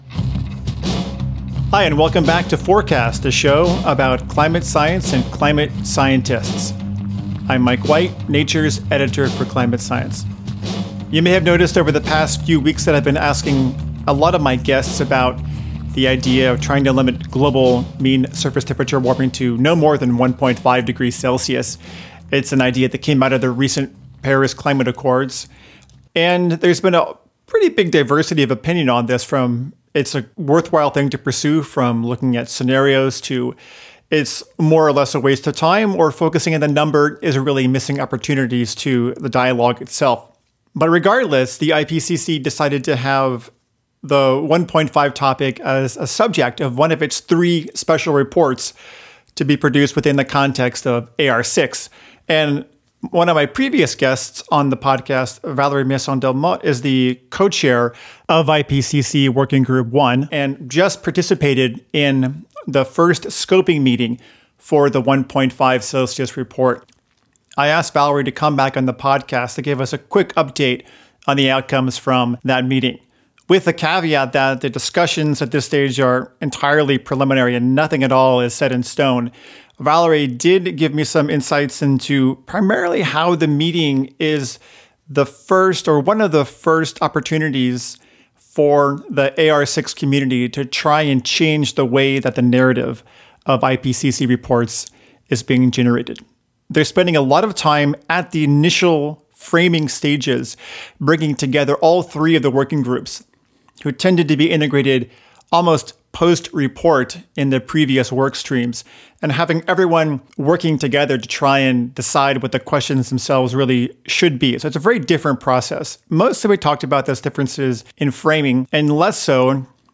IPCC Working Group I co-chair Valérie Masson-Delmotte kindly agreed to come back on the podcast for a quick catch-up on the recent 1.5 scoping meeting held in Geneva.